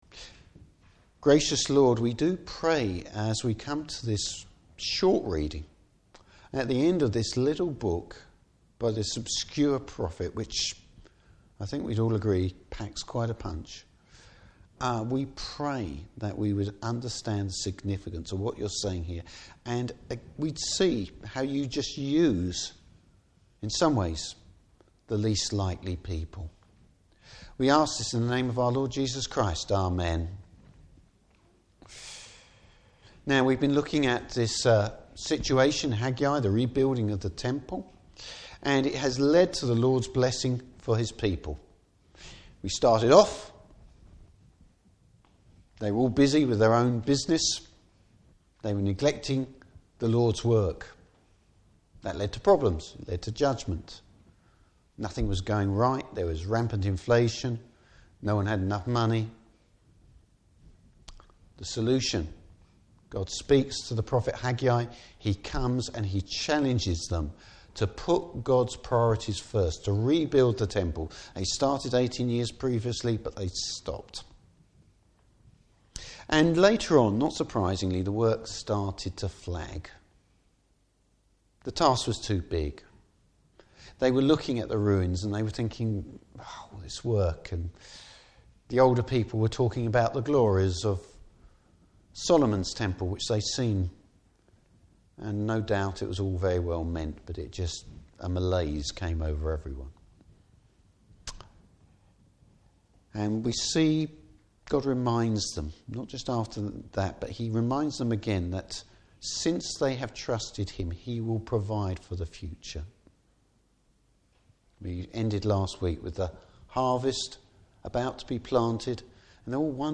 Service Type: Morning Service What God will do through ordinary people.